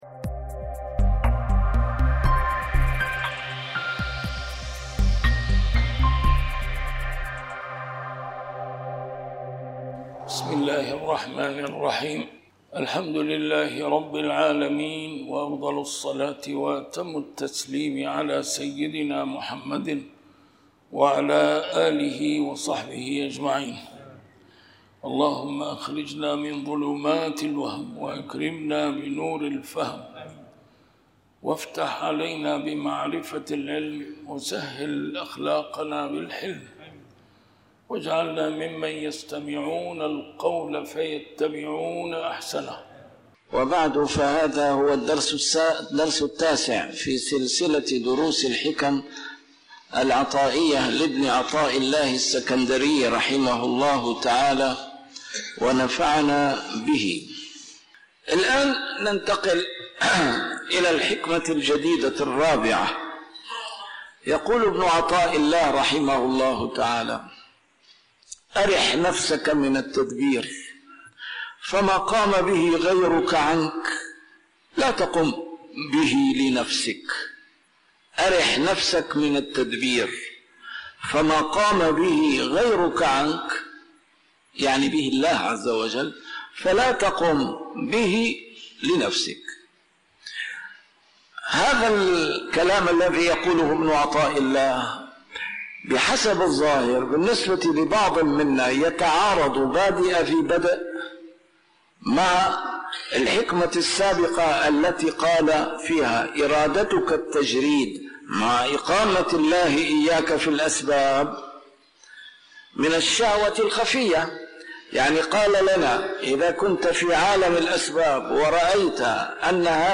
A MARTYR SCHOLAR: IMAM MUHAMMAD SAEED RAMADAN AL-BOUTI - الدروس العلمية - شرح الحكم العطائية - الدرس رقم 9 شرح الحكمة 4